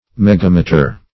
megameter - definition of megameter - synonyms, pronunciation, spelling from Free Dictionary
Megameter \Meg"a*me`ter\, Megametre \Meg"a*me`tre\, n. [Mega- +